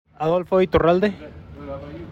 ⇓ Name Pronunciation ⇓